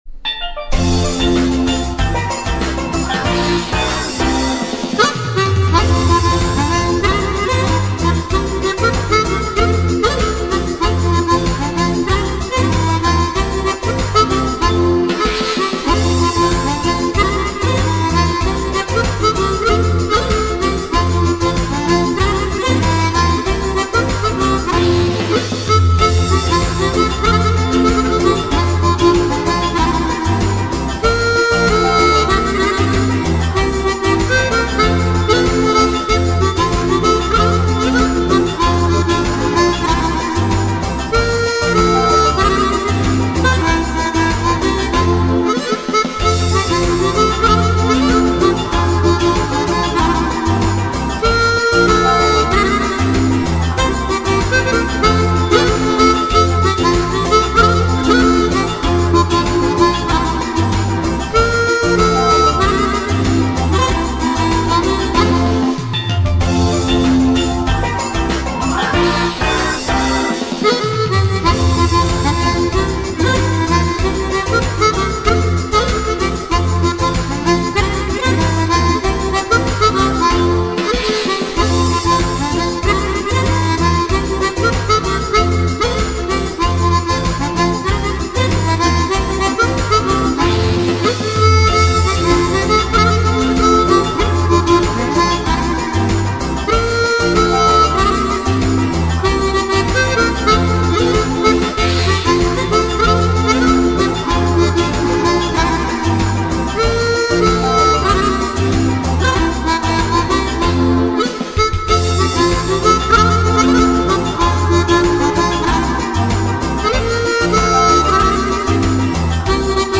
version harmonica